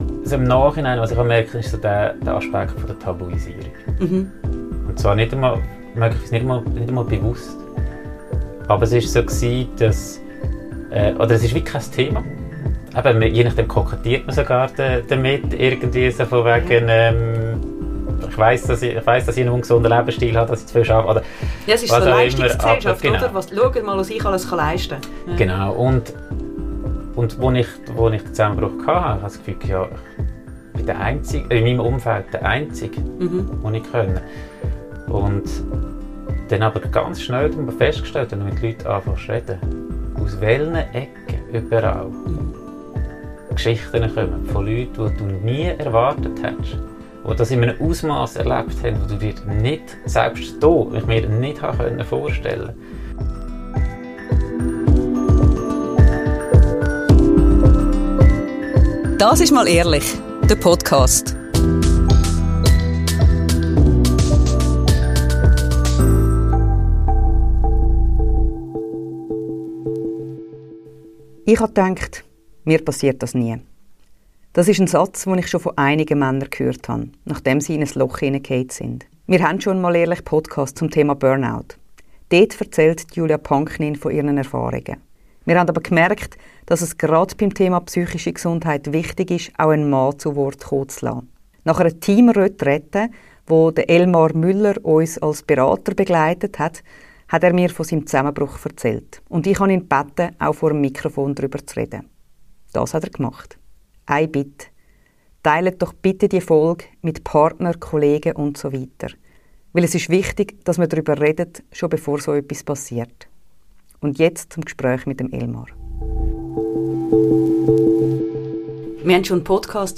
Gespräche rund um Elternsein, persönliche Bedürfnisse und Gesellschaftsthemen.